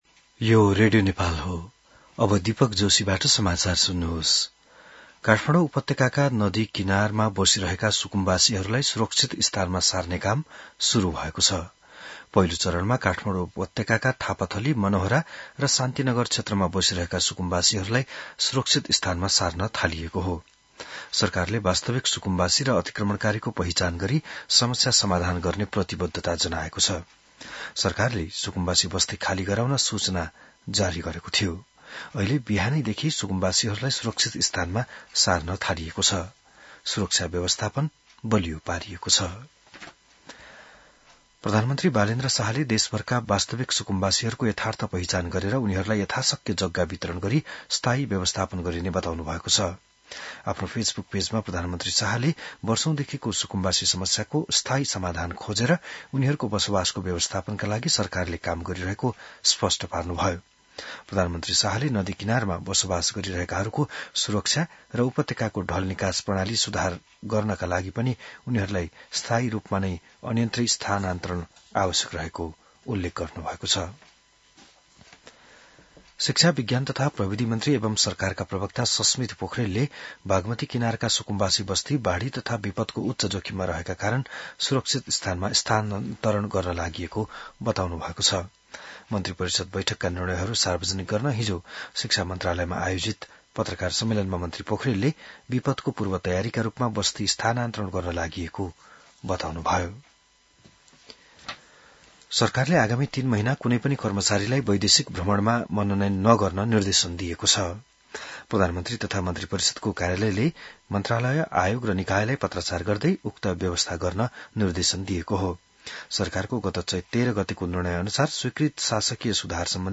बिहान १० बजेको नेपाली समाचार : १२ वैशाख , २०८३